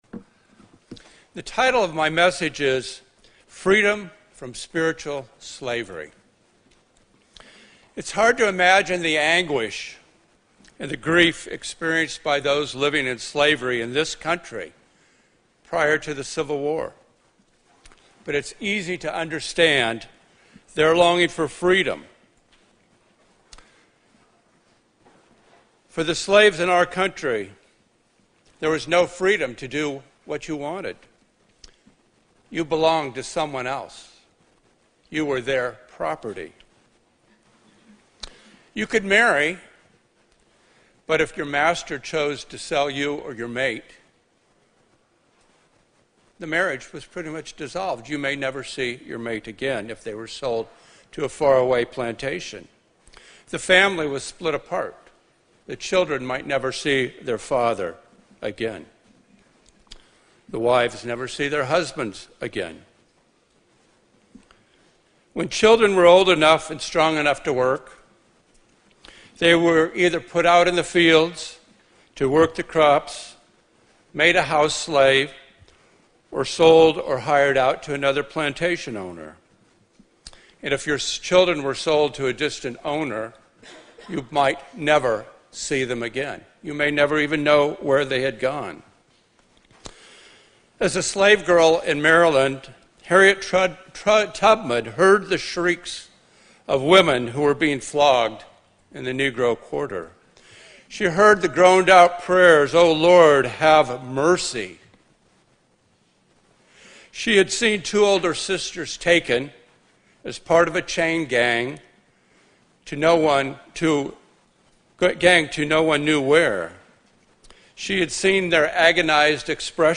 Sermons
Feast of Tabernacles 2020 - Berlin, OH
Given in North Canton, OH